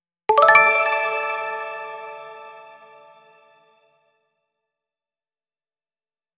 NewOrderAlert2.mp3